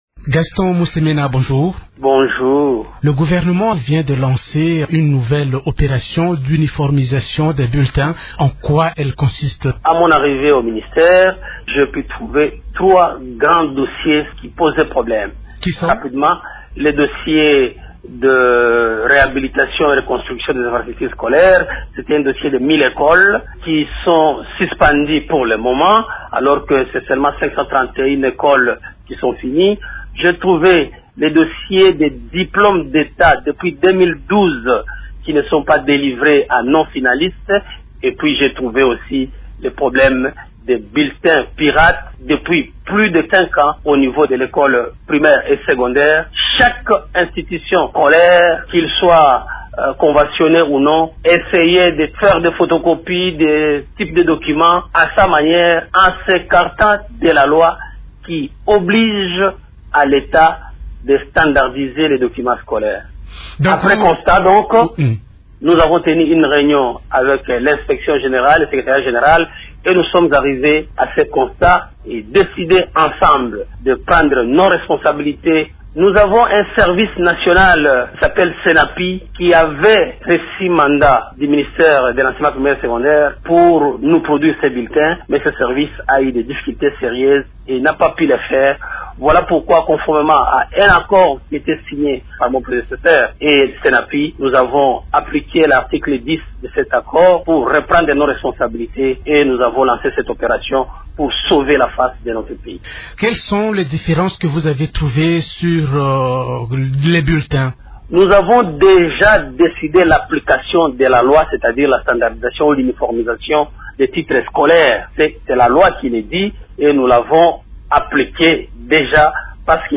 Invité de Radio Okapi de mercredi 19 avril, il a indiqué avoir pris cette décision après avoir remarqué la circulation depuis un laps de temps des bulletins pirates dans les écoles, au détriment de l’enseignement.